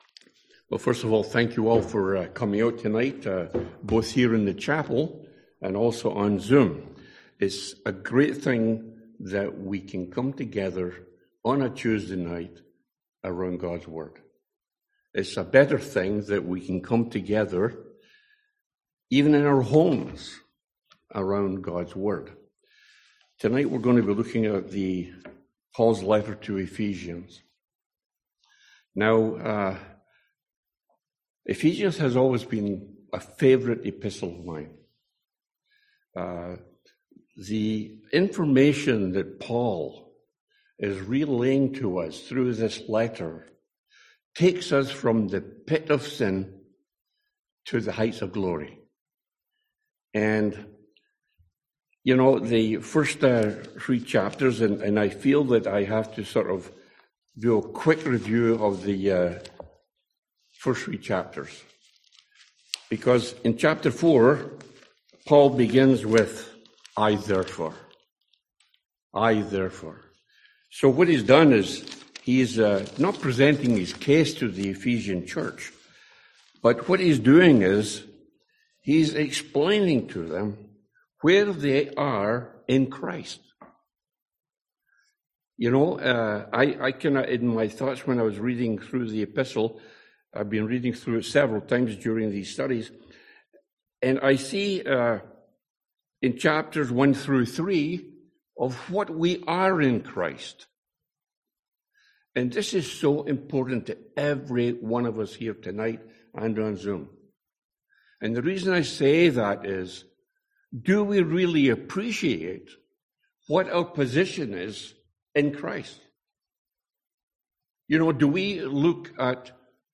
Passage: Ephesians 4:1-16 Service Type: Sunday AM